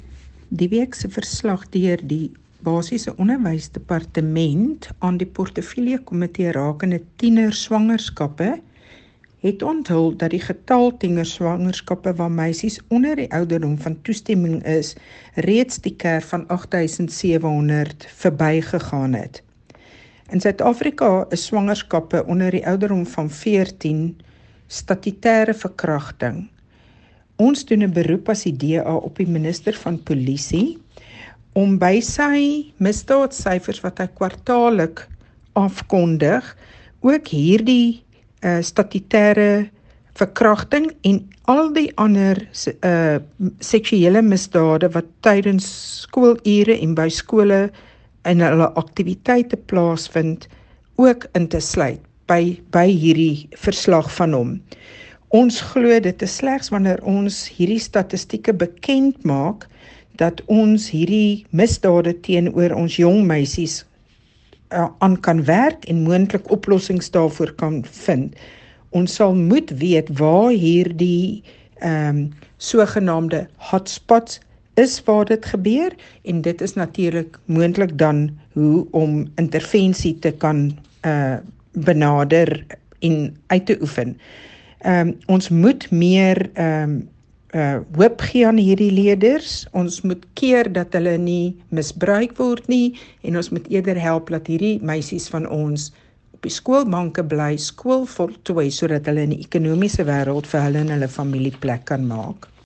Afrikaans by Desiree van der Walt MP